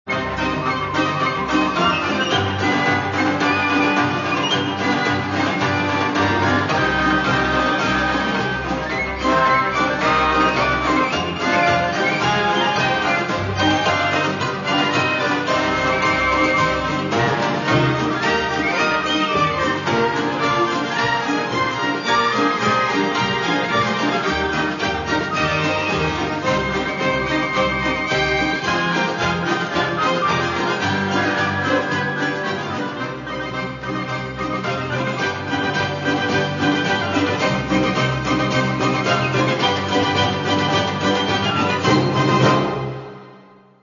Каталог -> Народна -> Збірки